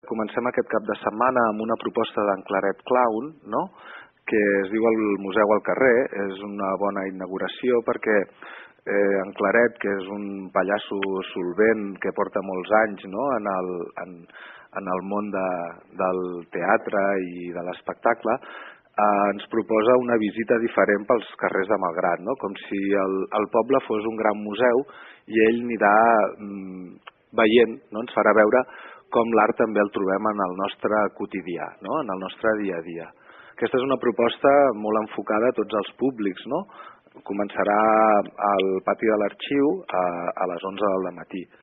L’esdeveniment, que va ser presentat aquesta mateixa setmana a Malgrat, té per objectiu acostar la cultura i l’art a la població, així com donar veu a artistes que segurament queden fora dels circuits comercials. N’ha parlat al programa Palafolls En Xarxa el regidor de cultura a l’Ajuntament de Malgrat de Mar, Paco Márquez.